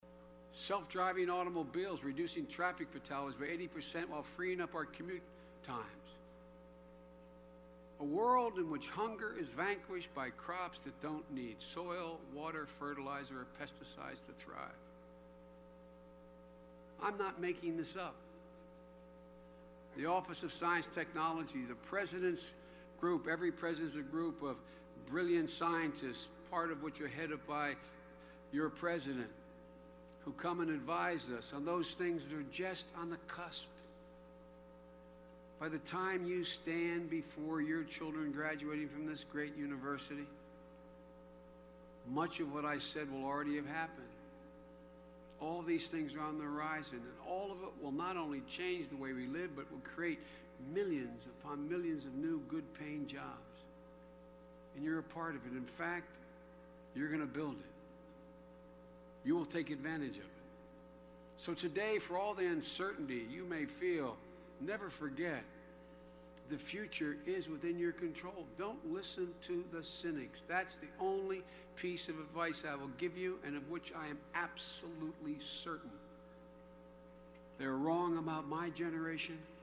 公众人物毕业演讲第414期:拜登2013宾夕法尼亚大学(12) 听力文件下载—在线英语听力室